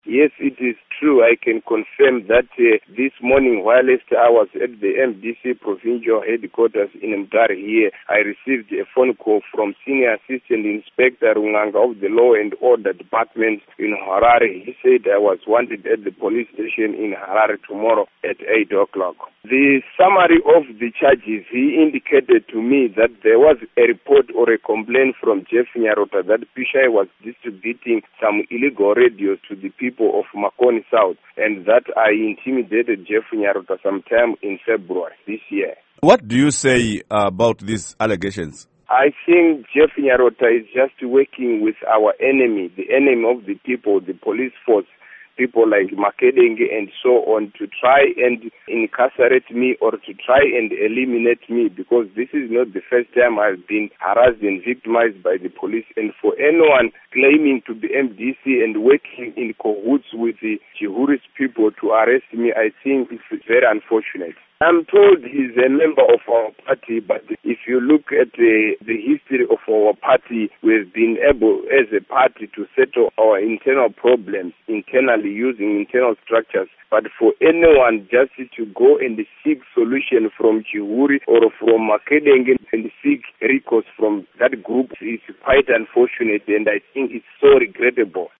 Interview With Pishai Muchauraya